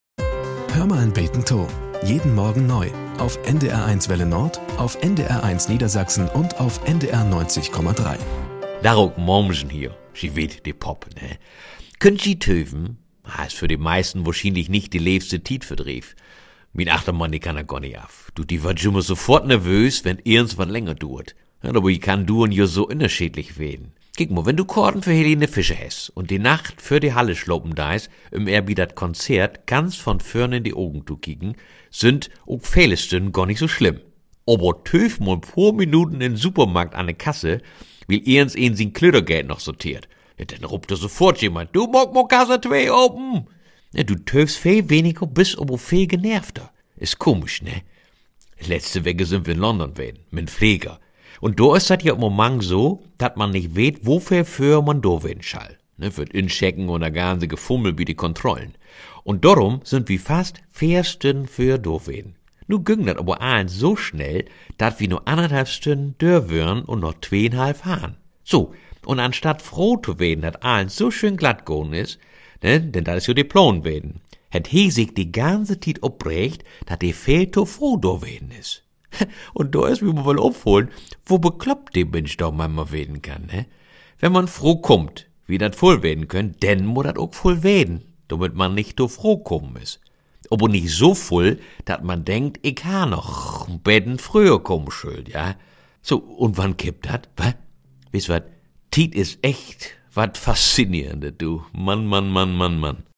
Nachrichten - 03.08.2023